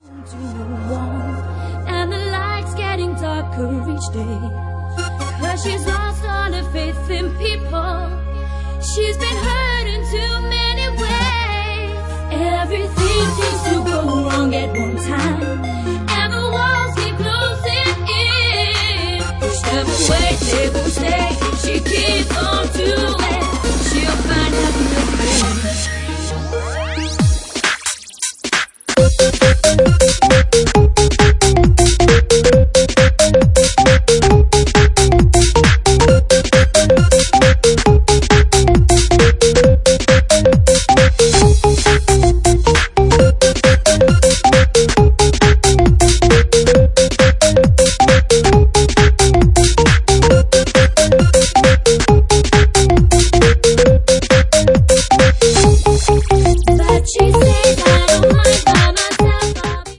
Genre:Bassline House
Bassline House at 135 bpm
big organ to bassline remix